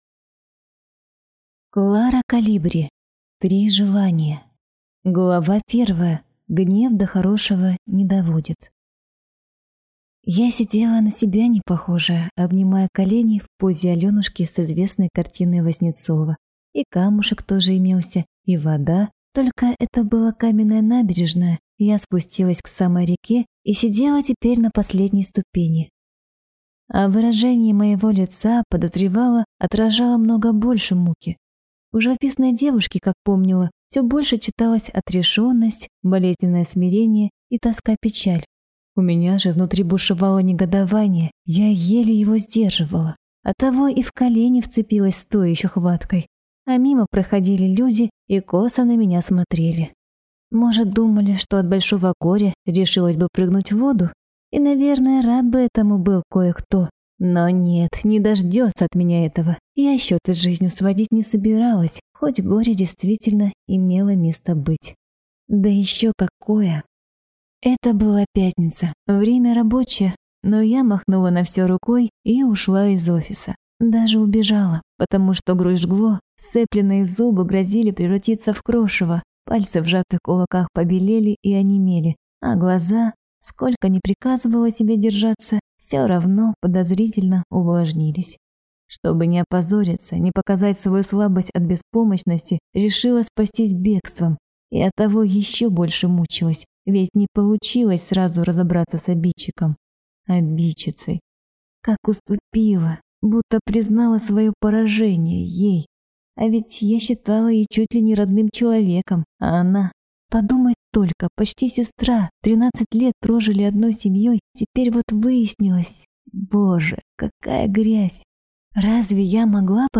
Аудиокнига Три желания | Библиотека аудиокниг